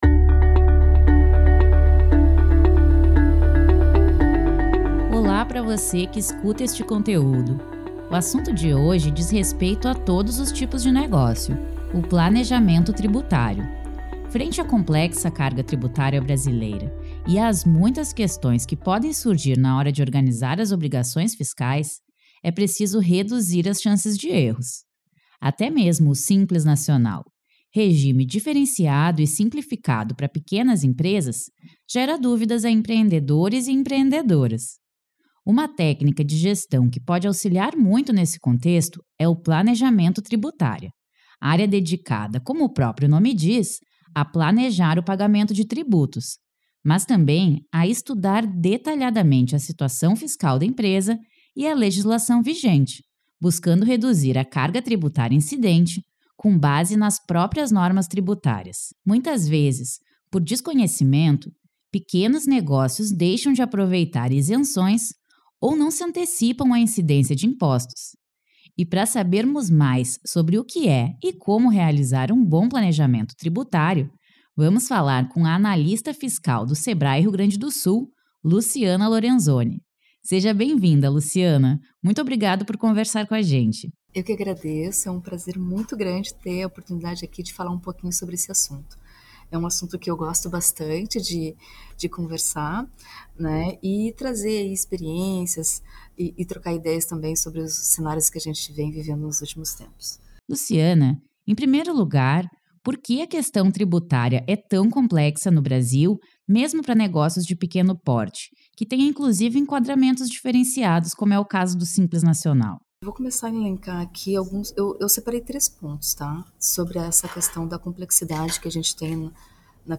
PODCAST-RS-Planejamento-Tributario.mp3